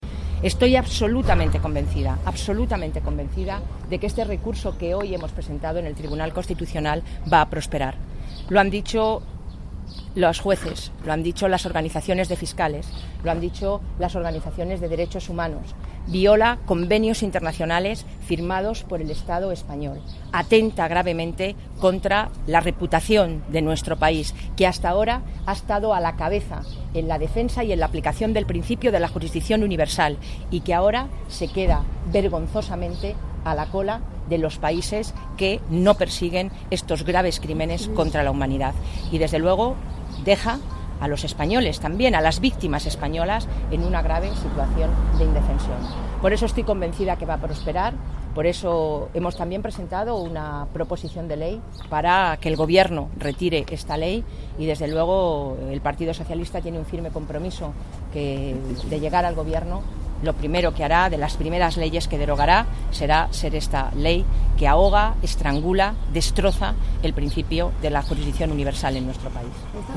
Declaraciones de Soraya Rodríguez tras presentar el recurso en el Tribunal Constitucional contra la reforma del PP que acaba con la justicia universal 12/06/2014